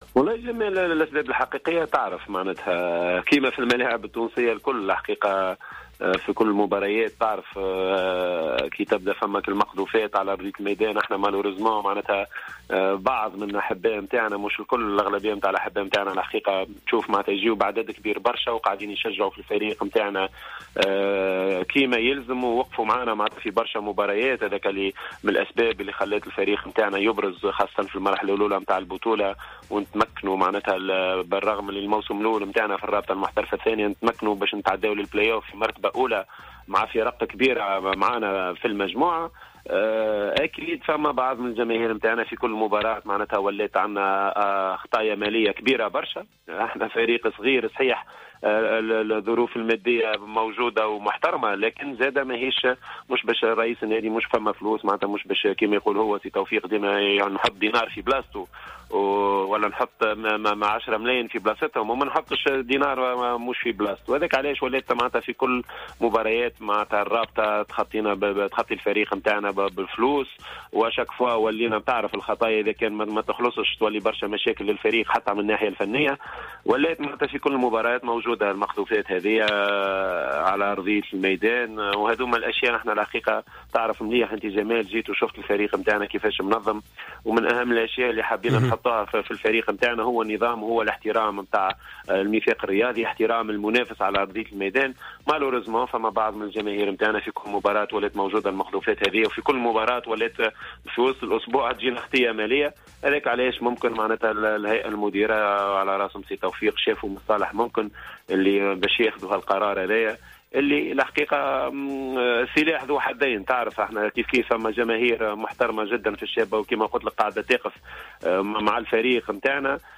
خلال مداخلته في برنامج راديو سبور